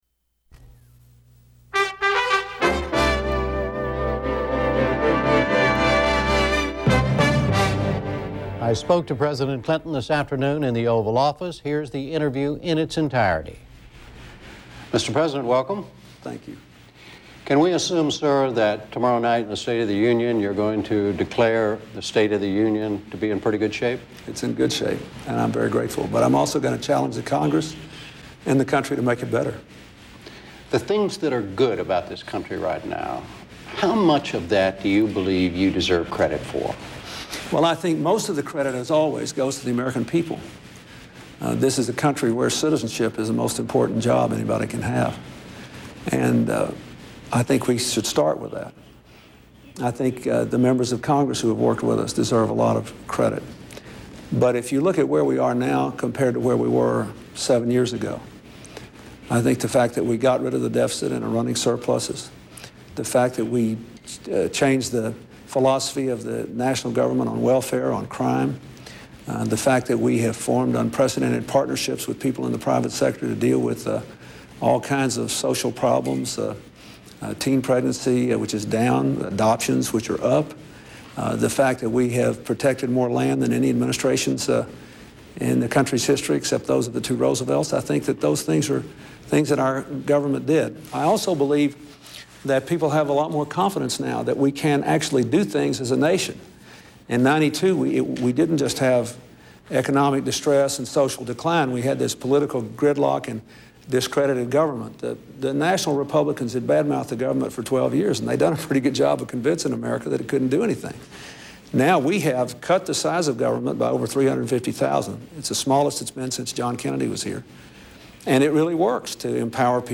Venue Note Broadcast on PBS-TV, News Hour, Jan. 26, 2000; 1900 EST, 1905 EST.